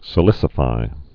(sĭ-lĭsə-fī)